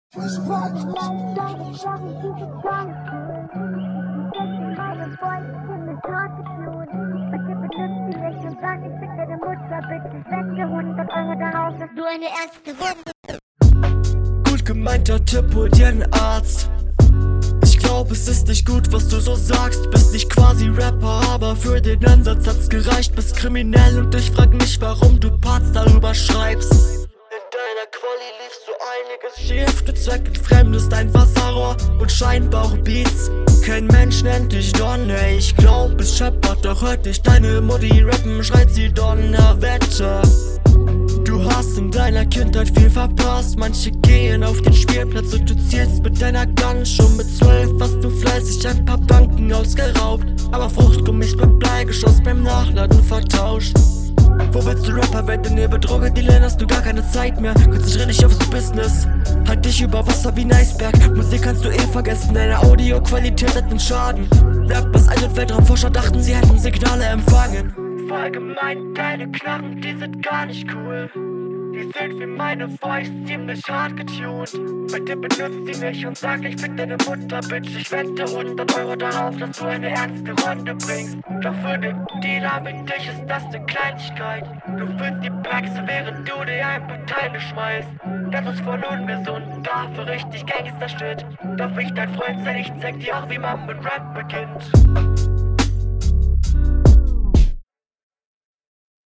wasn mit der verständlichkeit los?